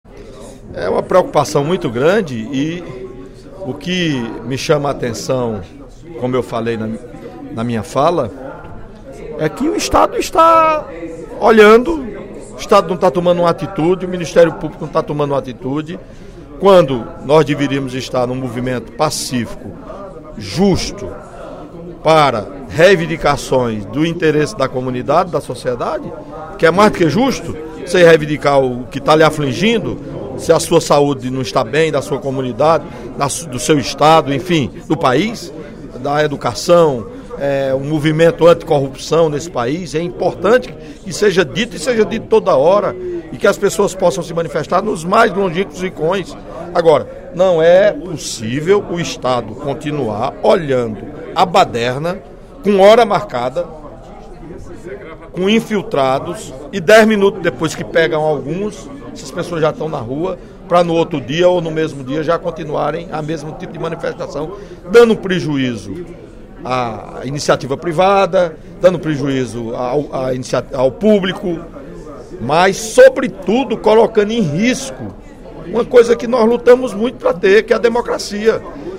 No primeiro expediente da sessão plenária desta quarta-feira (16/10), o deputado Welington Landim (Pros) lamentou o vandalismo promovido por pessoas mascaradas em protestos pacíficos realizados no País.